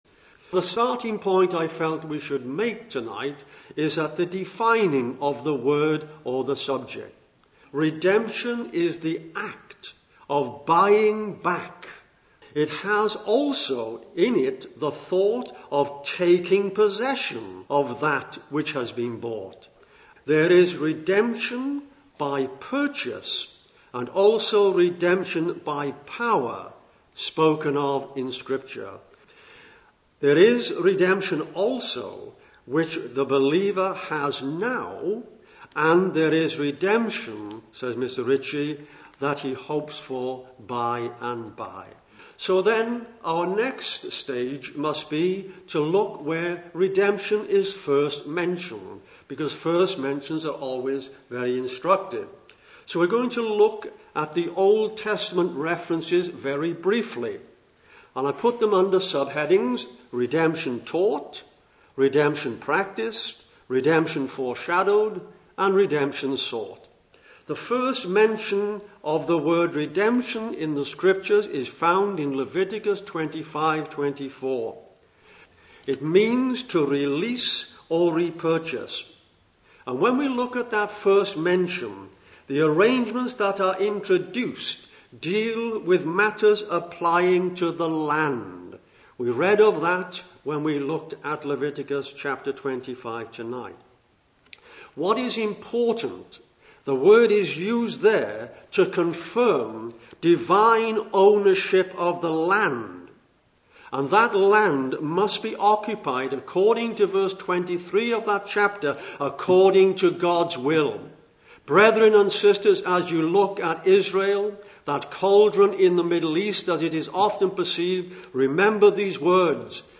(Message preached 14th June 2007)